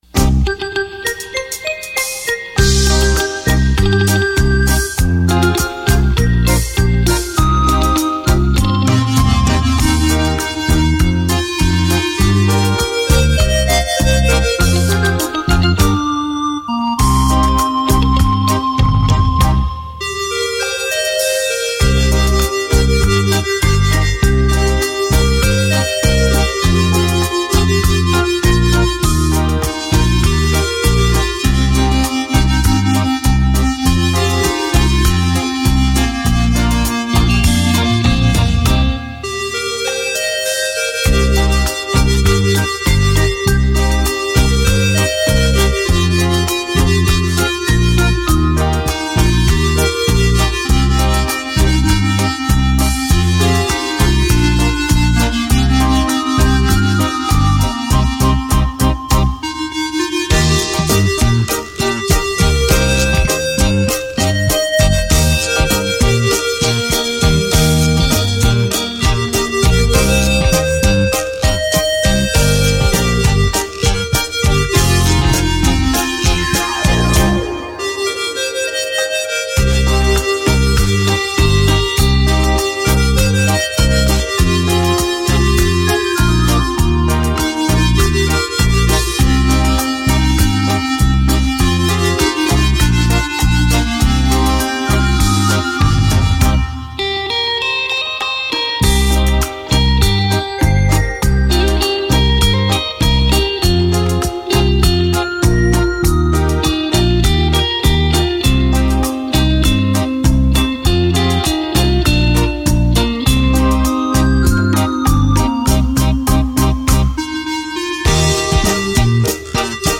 纯净晶莹 柔情似水的乐曲